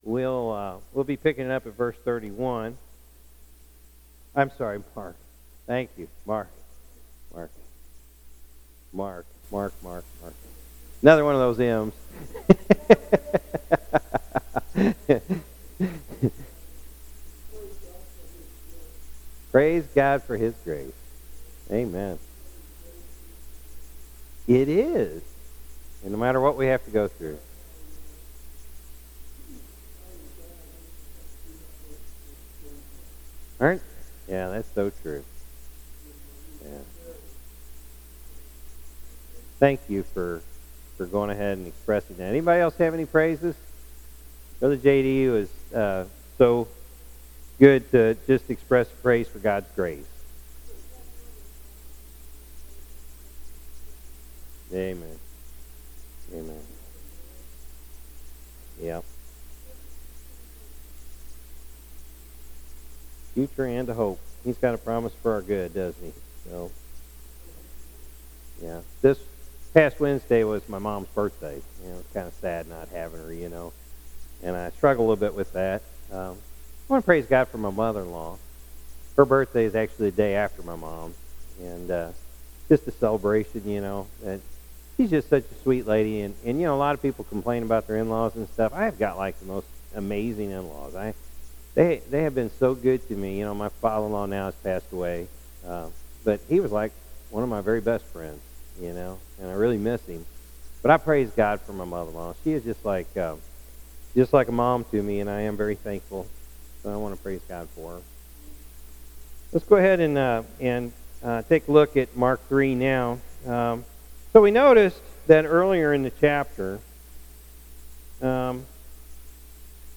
Evening Service Mark 3